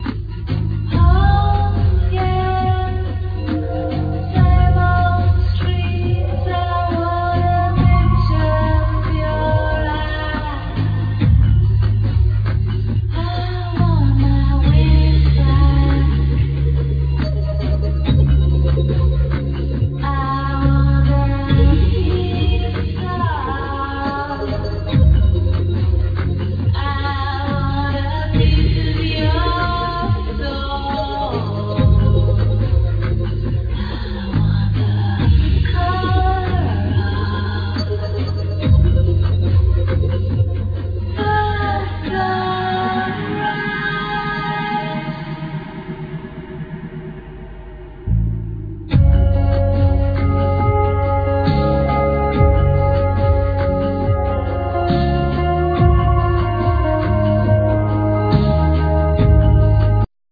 Vocals
Uillean pipes
Sax, Bass clarinet, Ba-wu flute
Acoustic guitar
Piano
Keyboards